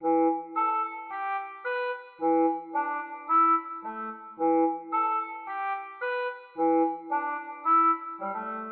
描述：这个循环是用Orchestral Oboe Vibrato均衡器制作的，并用TDR Nova进行母带处理。
Tag: 110 bpm Trap Loops Organ Loops 1.47 MB wav Key : C